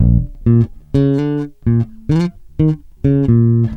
Loops de baixo 42 sons